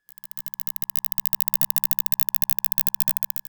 ihob/Assets/Extensions/RetroGamesSoundFX/Hum/Hum09.wav at master
Hum09.wav